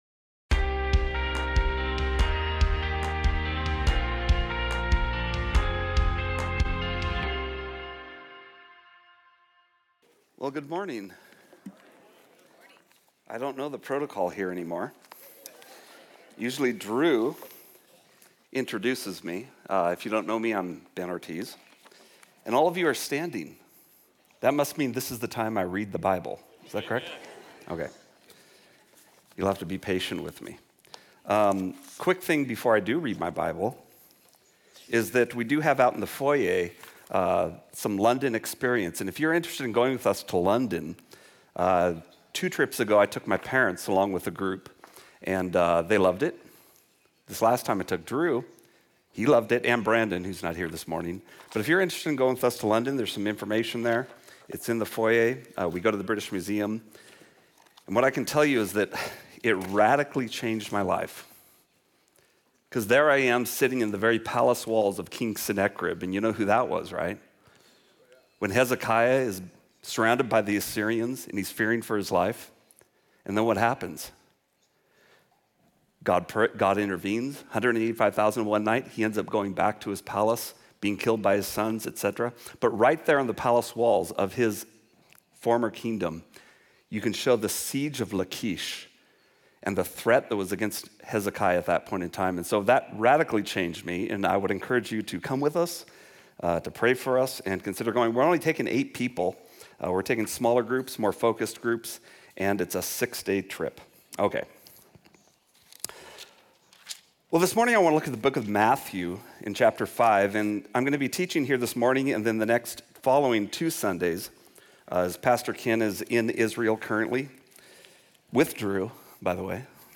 Part 1 The Beatitudes Calvary Spokane Sermon Of The Week podcast